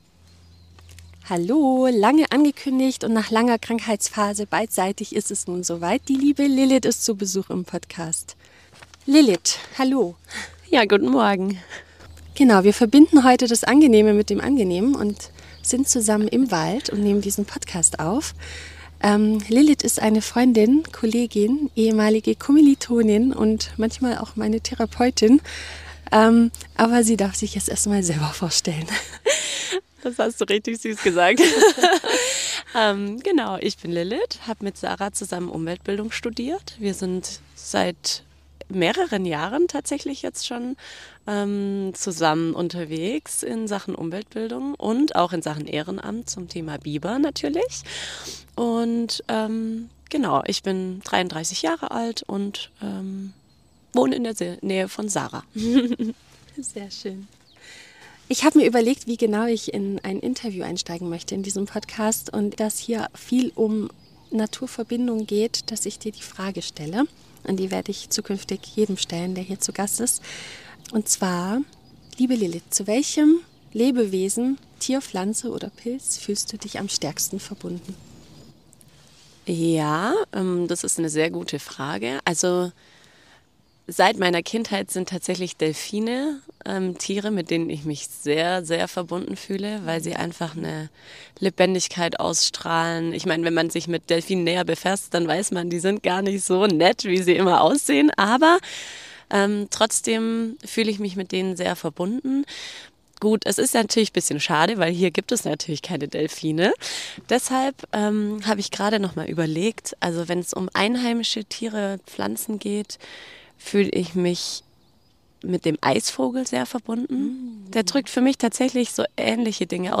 Ich spreche mit einer Expertin, die uns spannende Einblicke in die Welt der Biber gibt: Wie sie als wahre „Bauingenieure der Natur“ die Landschaften verändern und dabei eine entscheidende Ro...